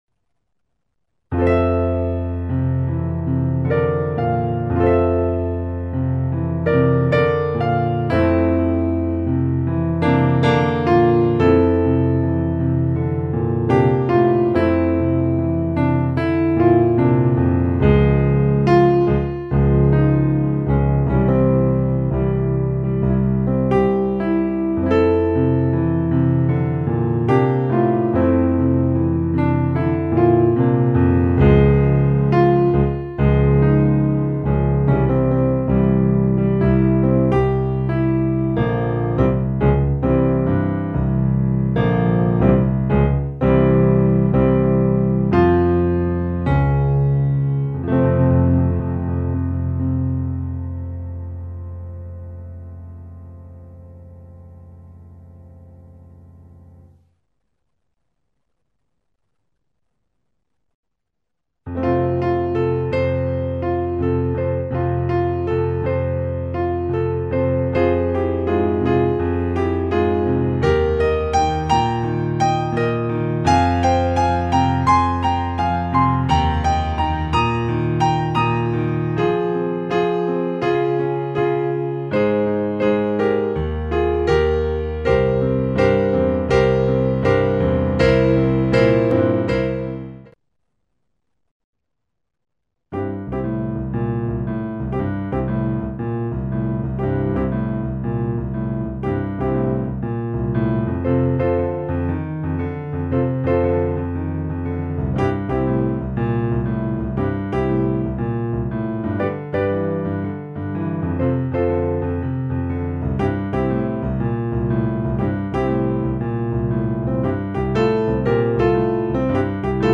Rock-Concert-Piano---varie.mp3